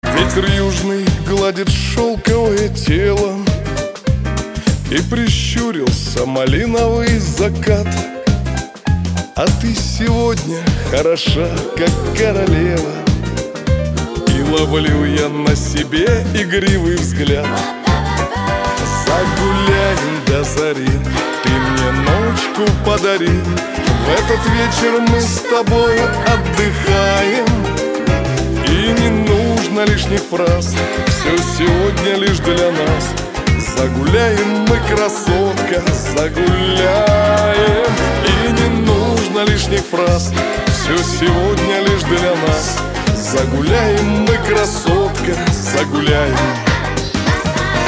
• Качество: 256, Stereo
мужской вокал
спокойные
русский шансон
женские голоса